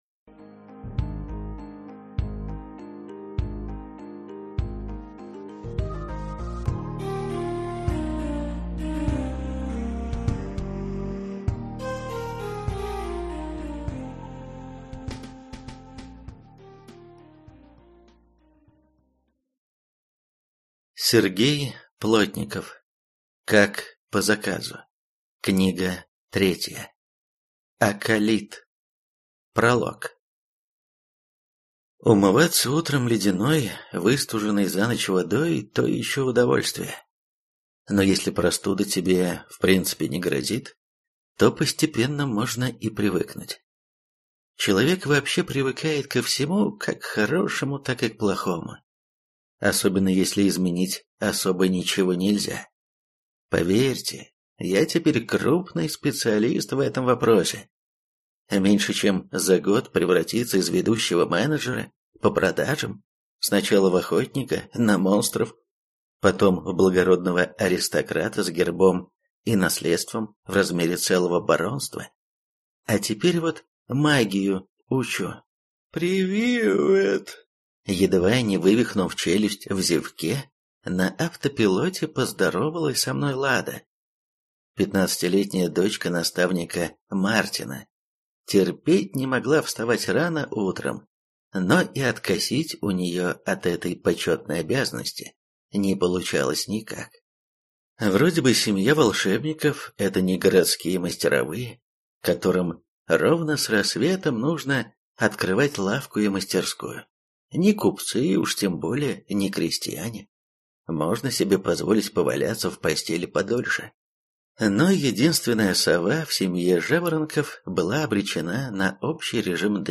Аудиокнига Аколит | Библиотека аудиокниг